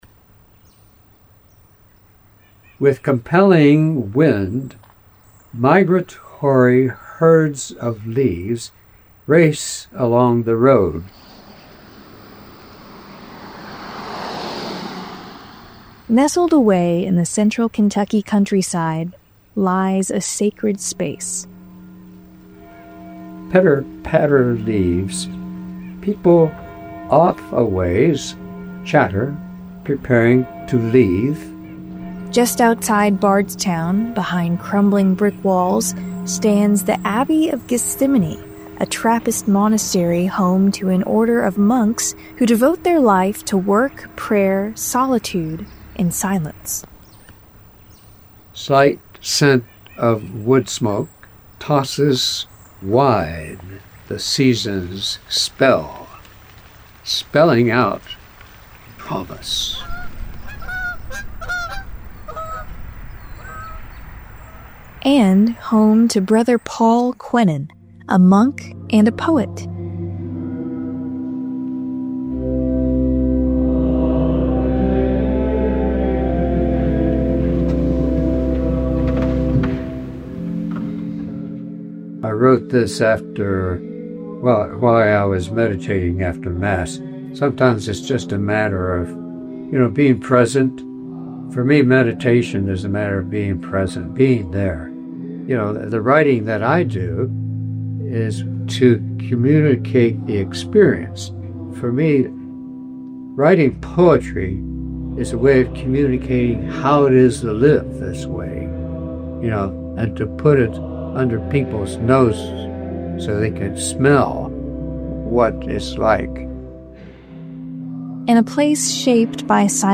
gethsemani-for-radio.mp3